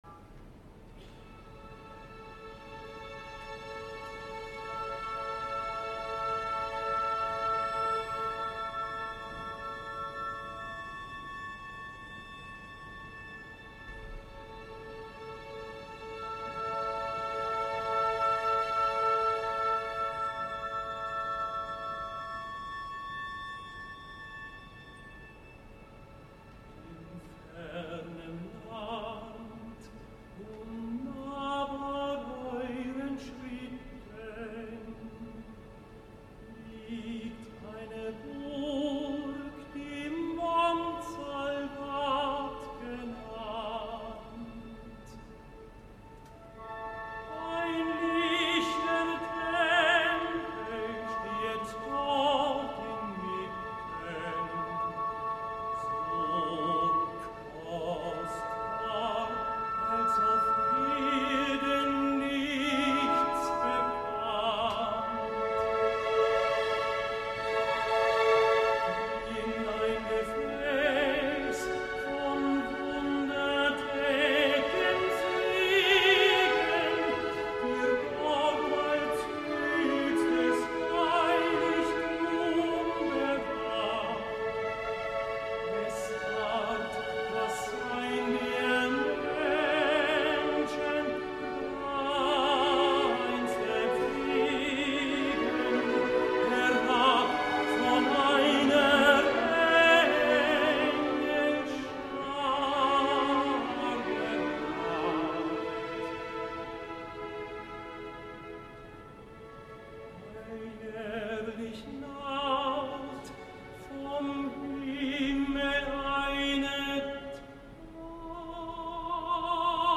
Els quatre tenors, magnífics tots ells com a Lohengrin, que formaven una única unitat en el In Fernem Land eren actuals, o quasi, ja que un d’ells el segon deu estar a punt de retirar-se vist l’estat actual de la seva veu.
Klaus Floriant Vogt  a Bayreuth
Ben Heppner a Dresden
Johan Botha al Japó
Jonas Kaufmann a la Scala